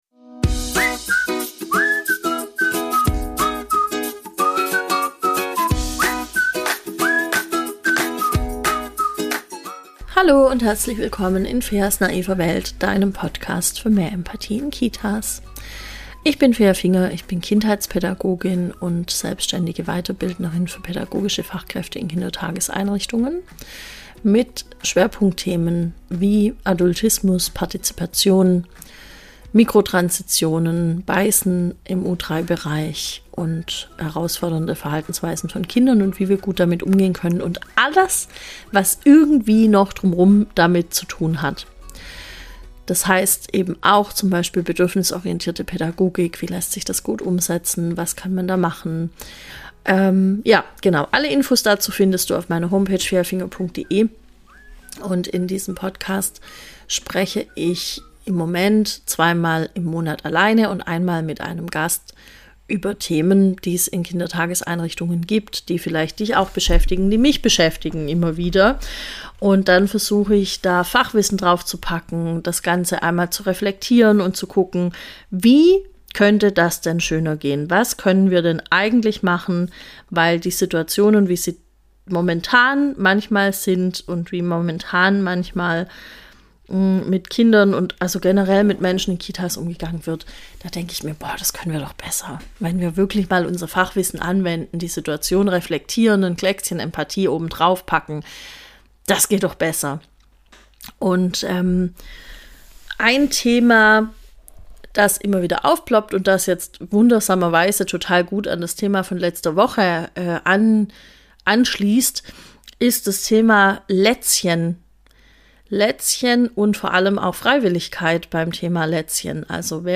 Interviewspecial